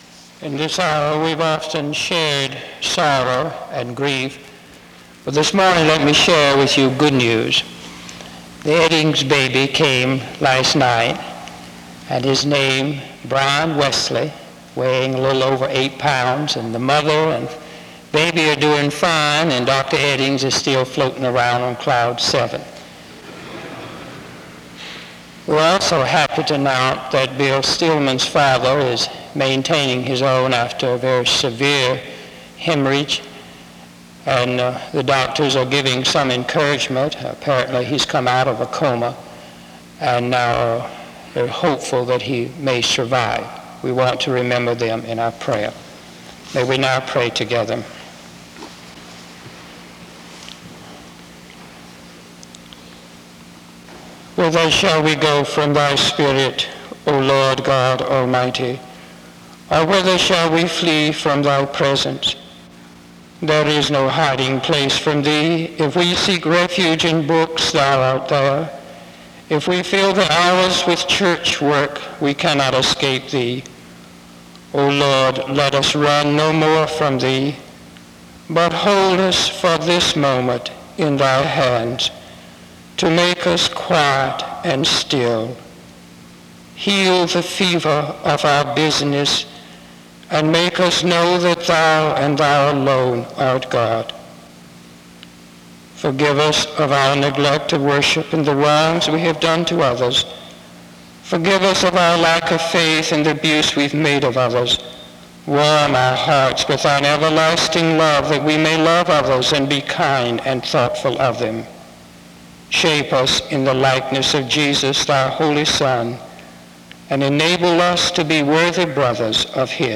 The service begins with announcements from 0:00-0:46. A prayer is offered from 0:47-2:48. An introduction to the speaker is given from 2:58-4:19.
He makes a plea to the chapel to preach the gospel faithfully. A closing prayer is offered from 27:19-28:40.